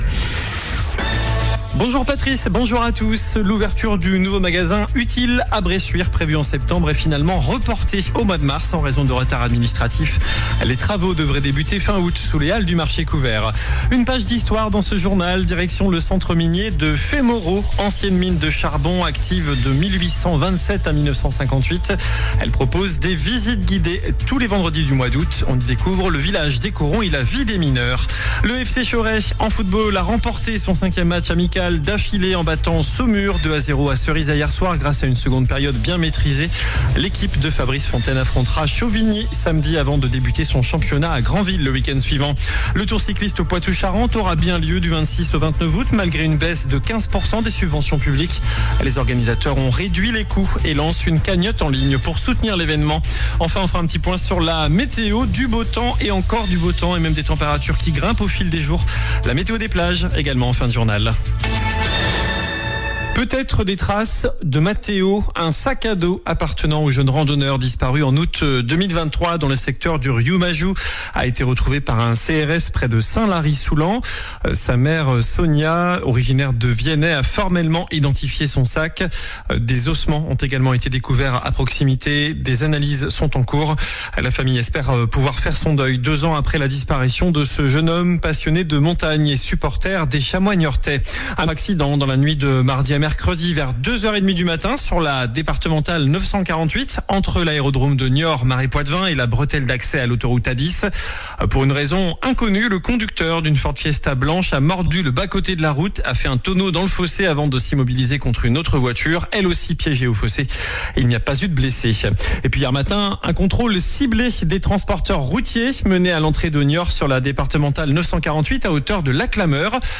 JOURNAL DU JEUDI 07 AOÛT ( MIDI )